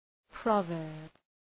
Προφορά
{‘prɒvɜ:rb}